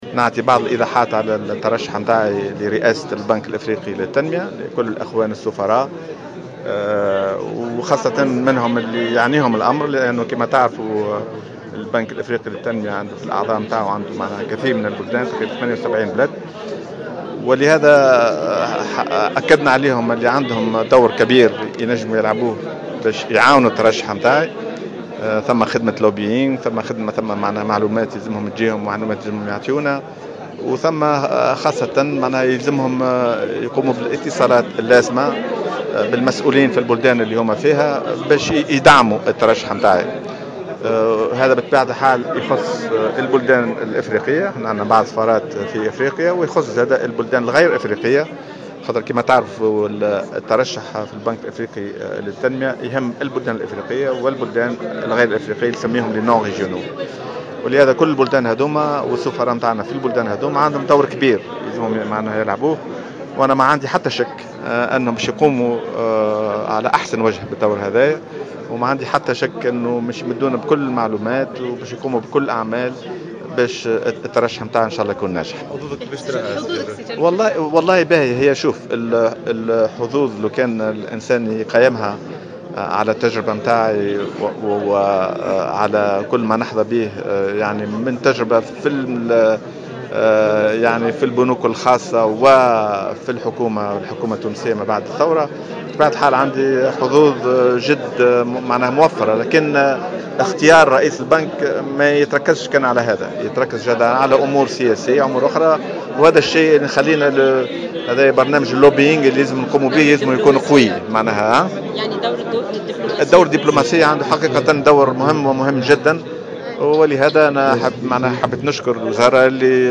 Jalloul Ayed, économiste et homme d’affaires, a tenu à démentir ce mardi dans une déclaration aux médias les rumeurs rapportant sa candidature à la tête d’une liste du mouvement Nidaa Tounes pour les prochaines élections législatives.